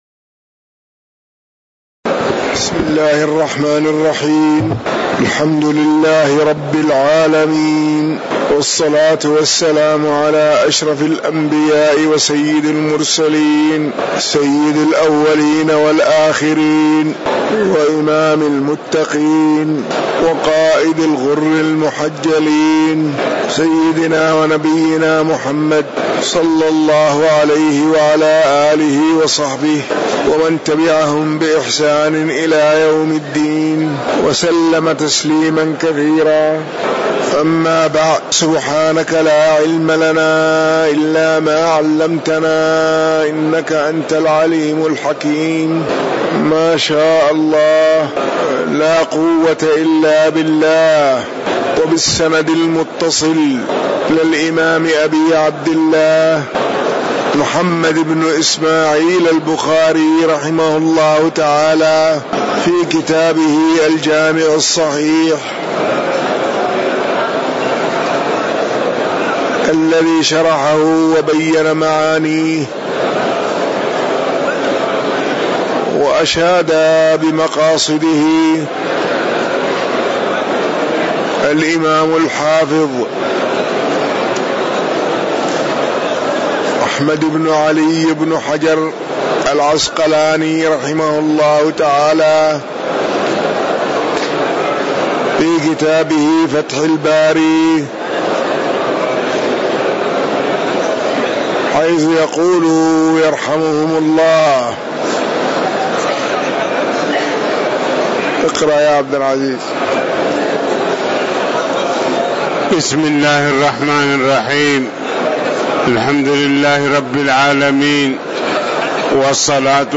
تاريخ النشر ٢٠ جمادى الآخرة ١٤٤٠ هـ المكان: المسجد النبوي الشيخ